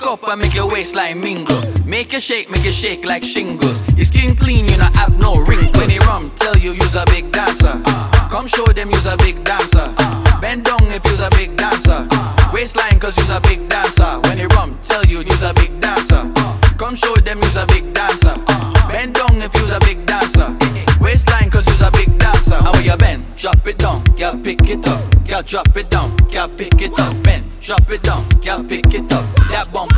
Off-beat гитары и расслабленный ритм
2025-01-24 Жанр: Регги Длительность